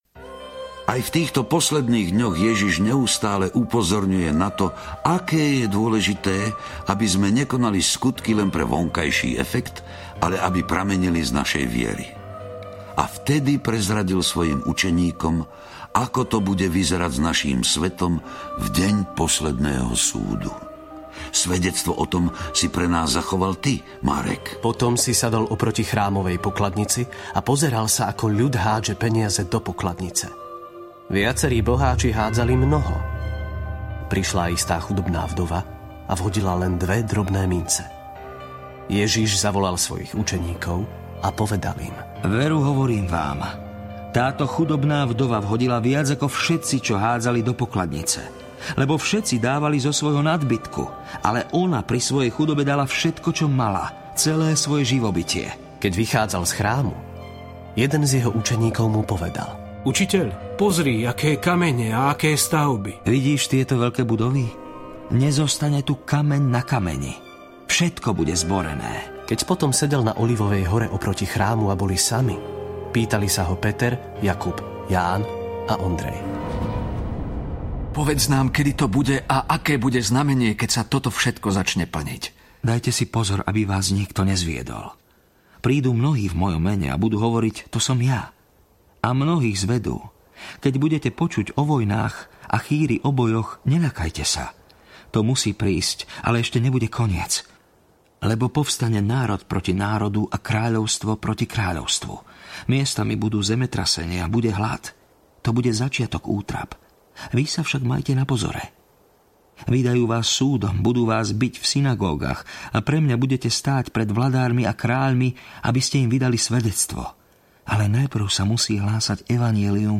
Biblia - Život Ježiša 4 audiokniha
Biblia - Život Ježiša 4 - dramatizované spracovanie Biblie podľa Nového zákona.
Ukázka z knihy